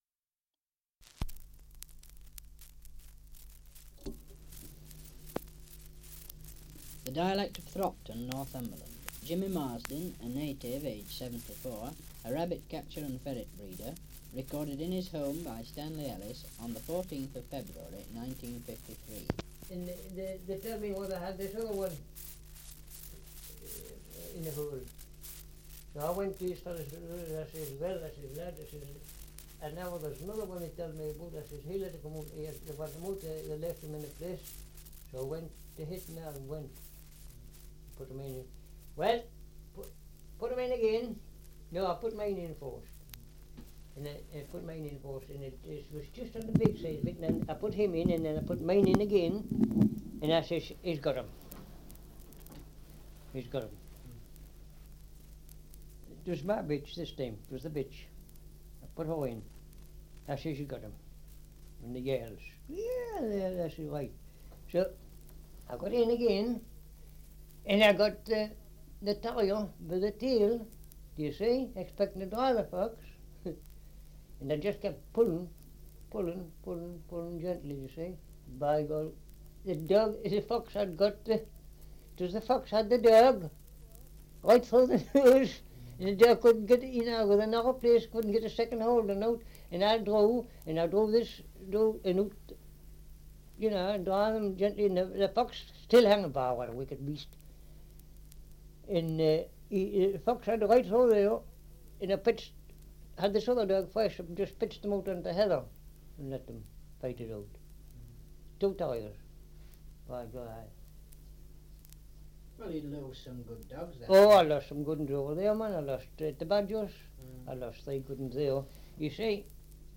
Survey of English Dialects recording in Thropton, Northumberland
78 r.p.m., cellulose nitrate on aluminium